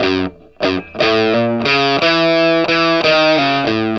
WALK1 60 FS.wav